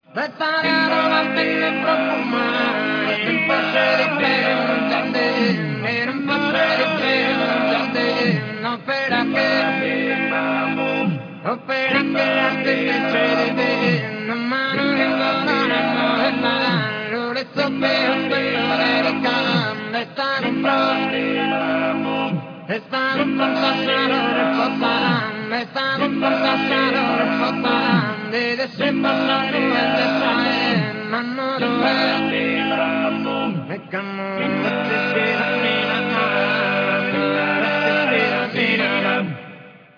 I wish to point out that the dances have a very ancient origin, surely preceding the Roman rule, and until the last century were performed only by human voices ( "a tenore" choir ) or reed pipes.
This file gives an idea of a musical expression among the most ancient in the world
rank collective dance
choir "a tenore" - 4 voices
coro "a tenore" - 4 voci